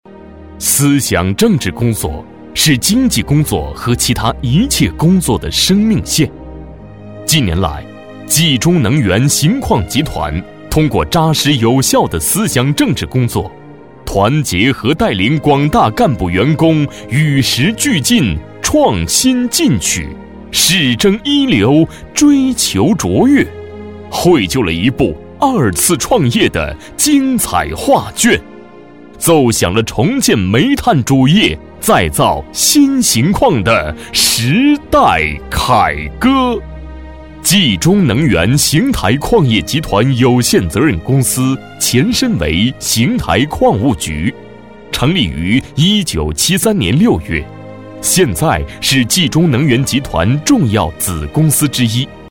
传统企业男31号（能源集团
大气稳重磁性男音。